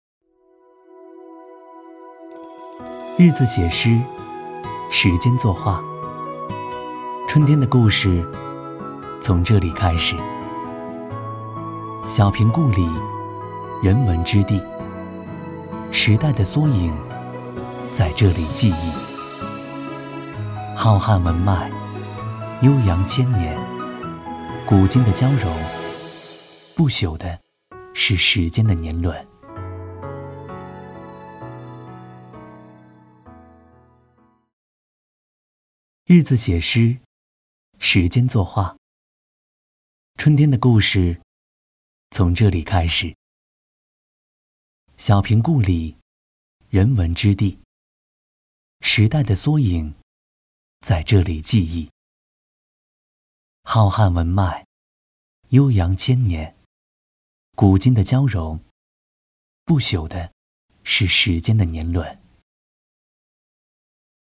特点：年轻时尚 轻松幽默 MG动画
风格:欢快配音
31男127系列-【温柔诉说】--下午茶 .mp3